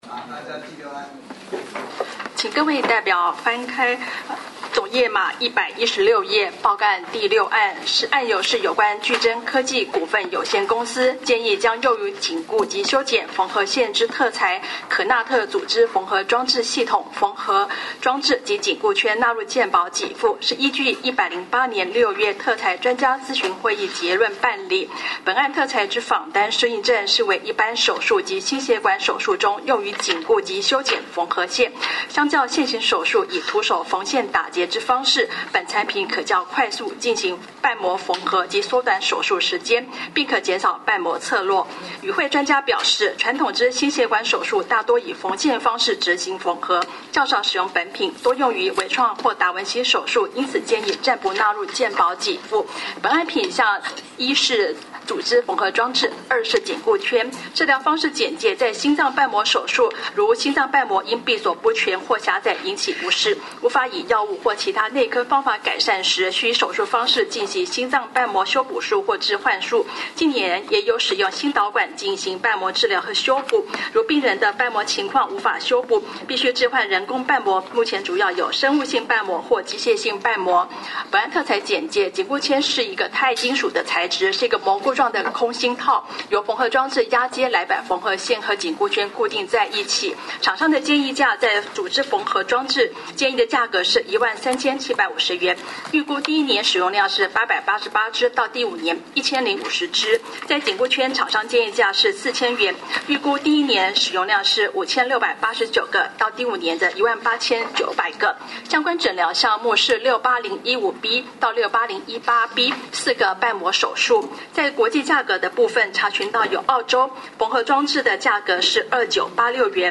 會議實錄錄音檔-108年7月